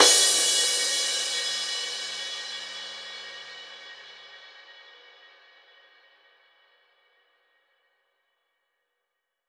edm-crash-04.wav